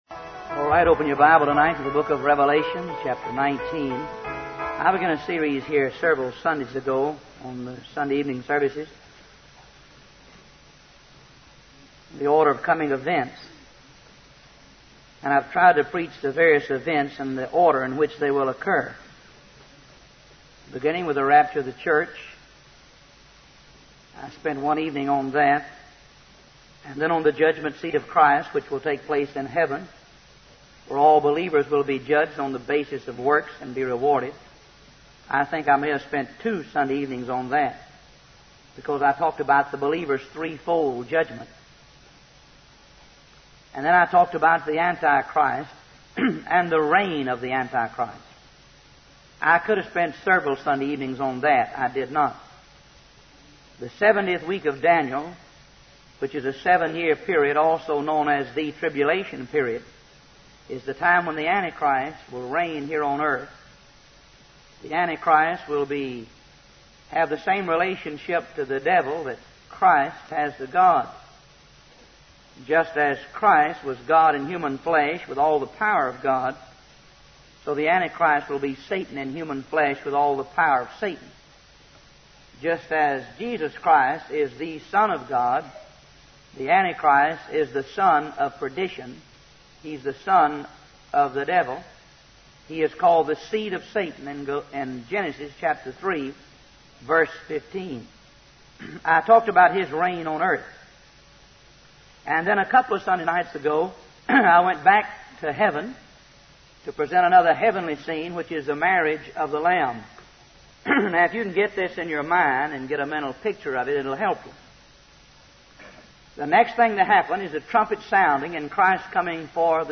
- Wise Desire Ministries helps convey various Christian videos and audio sermons.